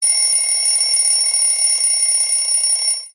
Categoria Sveglia